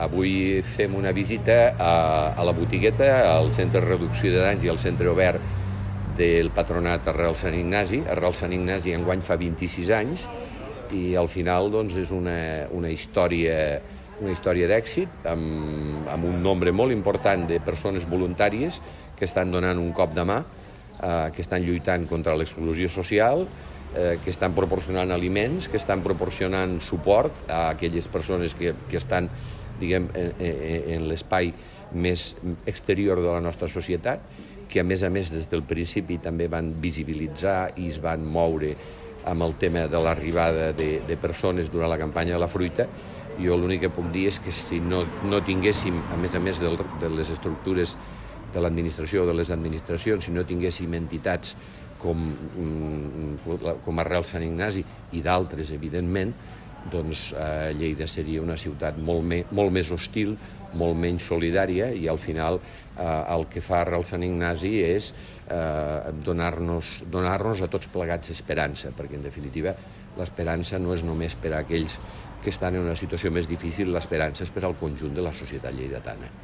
Tall de veu de Miquel Pueyo
tall-de-lalcalde-miquel-pueyo-sobre-la-visita-a-arrels